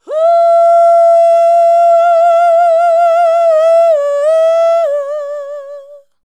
UUUH 3.wav